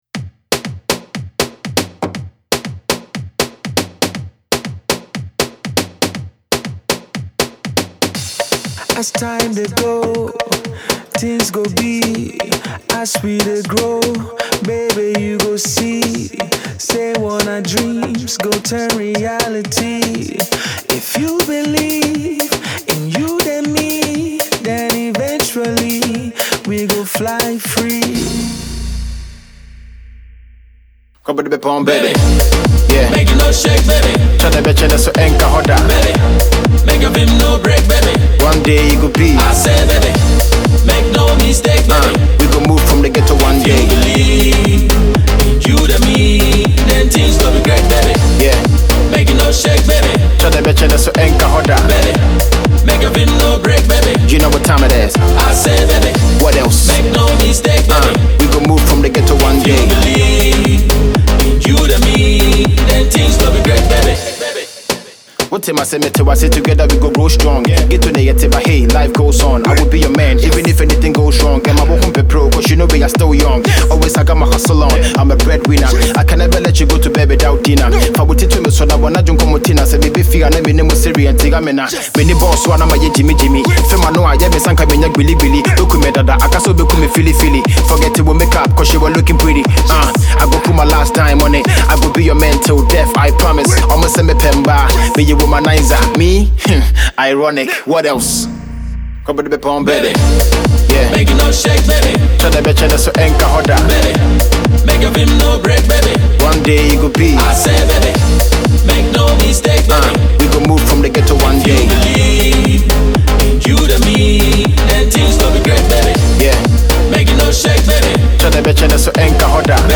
African Electronic Dance Music